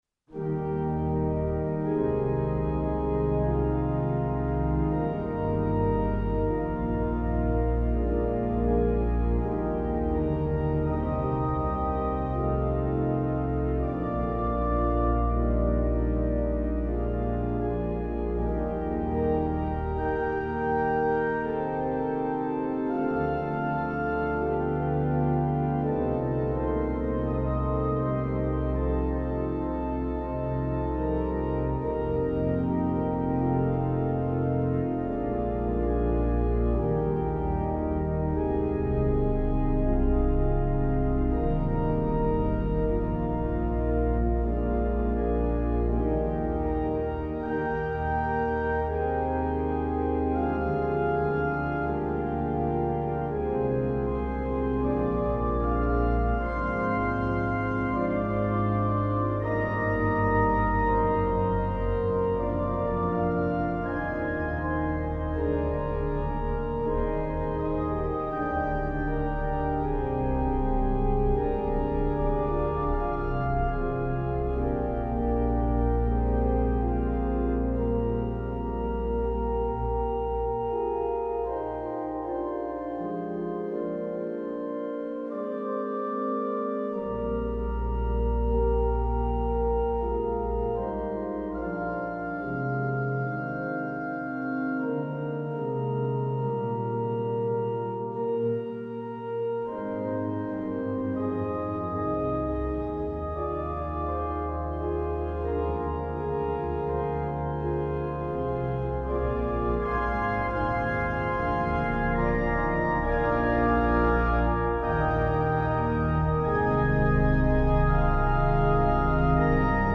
パイプオルガン「ニムロッド」記念日・追悼